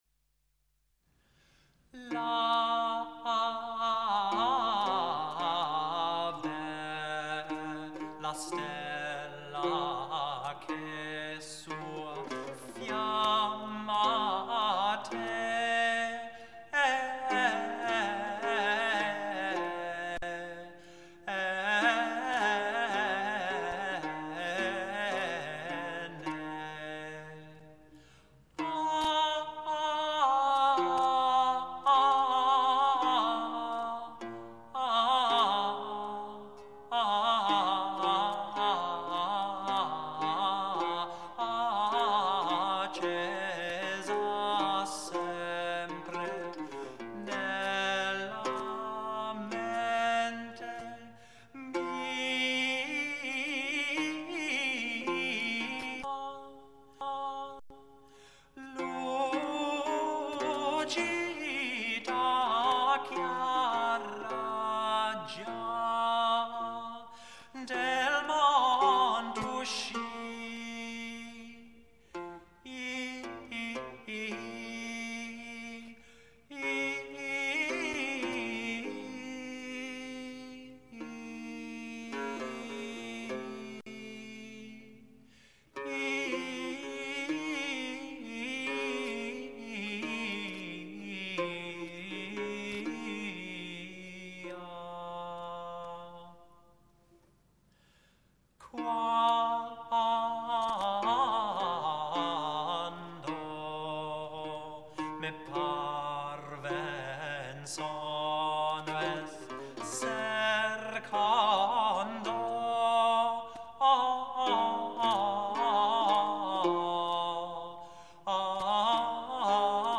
La bella stella - Madrigale